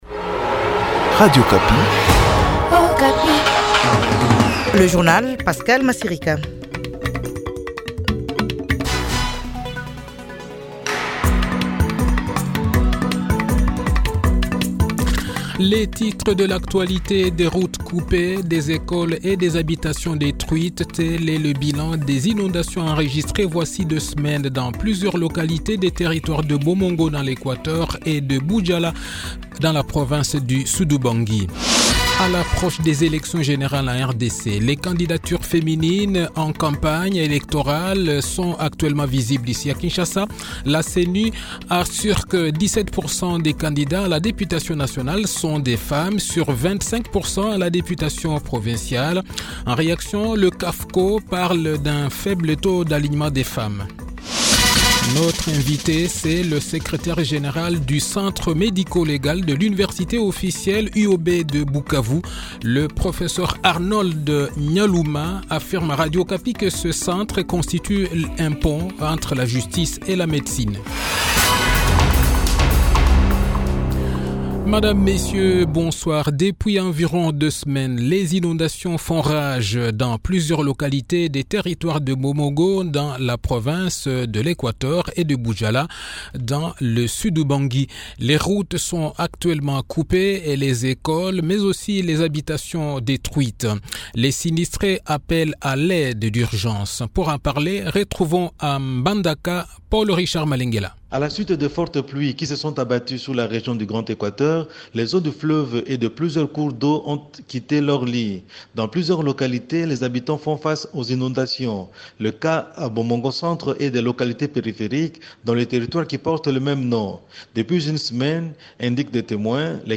Le journal de 18 h, 6 decembre 2023